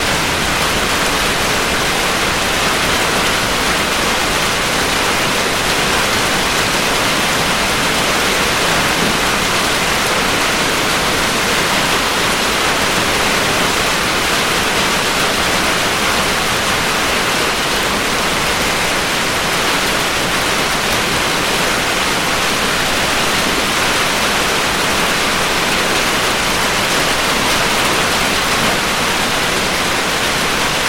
自然界 " 金属屋顶上的雨 5
描述：在波纹金属屋顶＃5的现场记录雨，麦克风在门口。
Tag: 暴风 天气 屋顶 金属 性质 现场录音